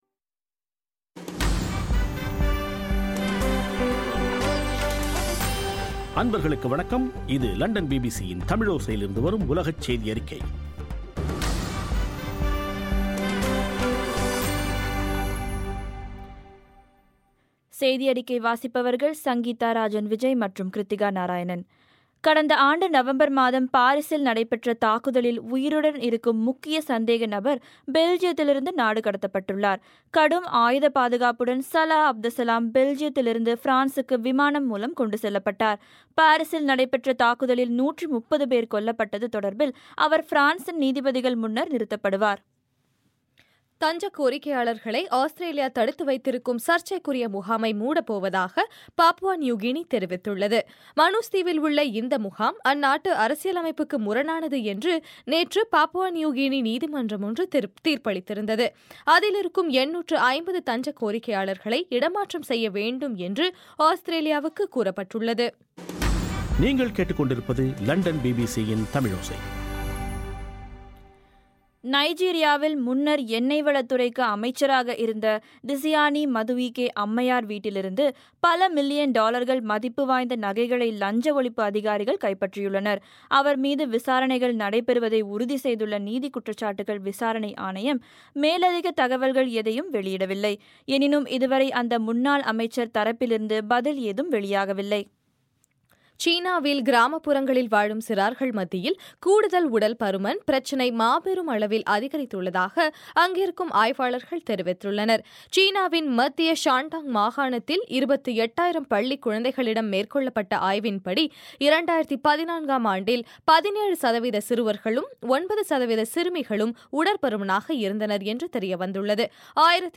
பிபிசி தமிழோசை- உலகச் செய்தியறிக்கை- ஏப்ரல் 27